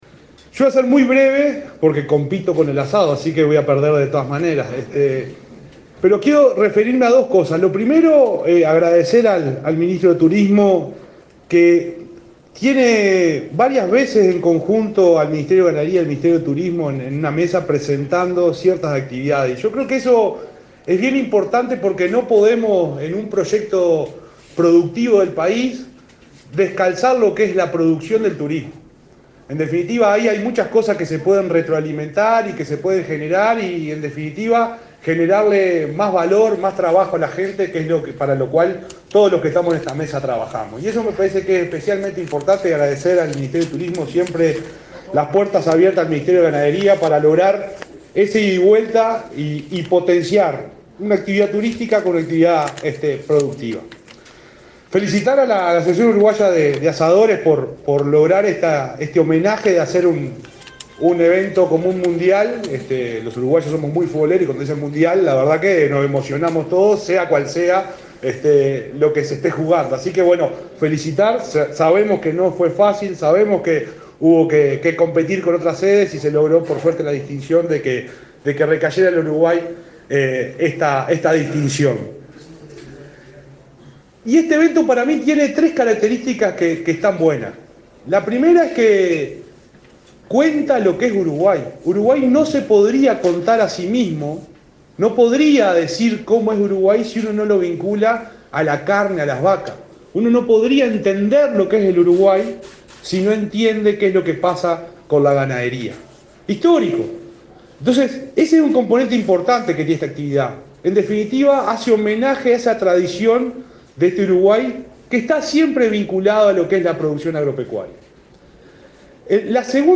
Palabras del subsecretario de Ganadería, Juan Ignacio Buffa
El subsecretario de Ganadería, Juan Ignacio Buffa, participó, este martes 5 en el Museo del Carnaval de Montevideo, en la presentación del Mundial de